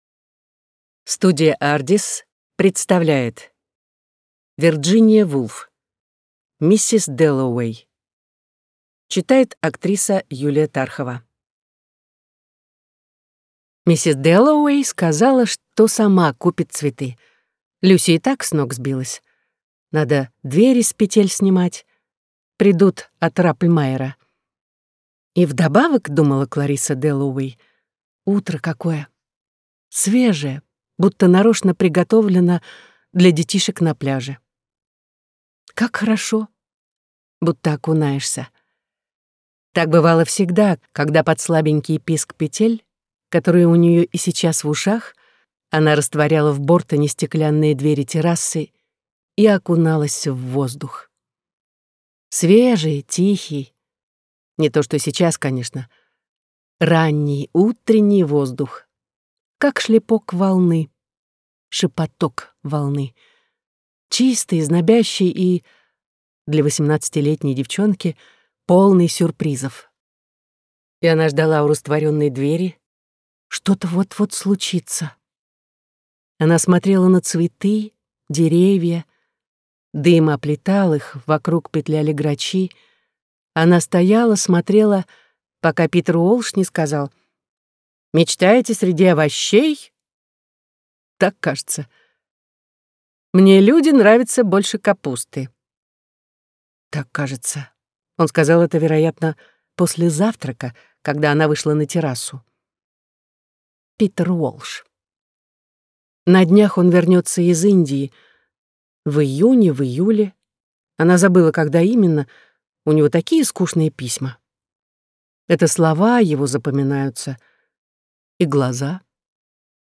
Аудиокнига Миссис Дэллоуэй | Библиотека аудиокниг